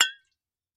Здесь собраны необычные аудиозаписи: переливы волшебных зелий, таинственные всплески и другие мистические эффекты.
Звон хрустальной бутылочки с эликсиром